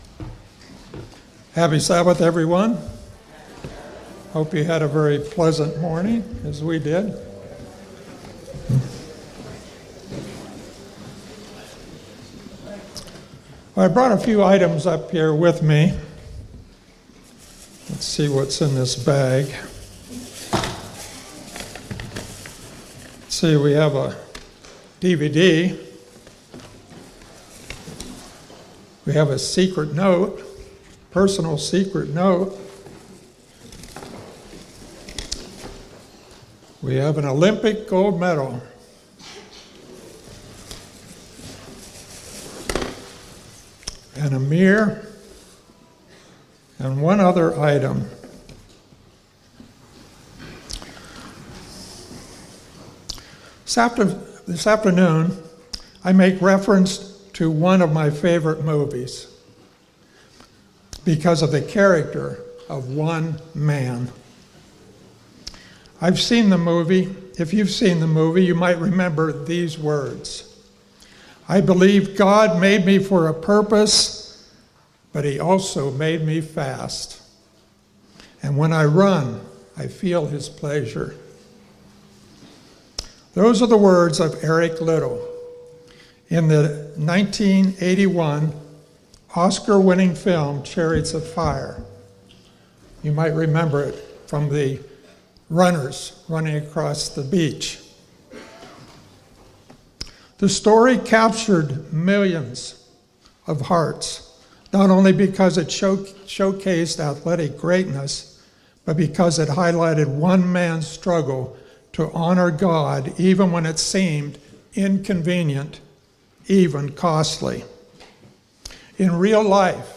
Sermons
Given in Walnut Creek, Ohio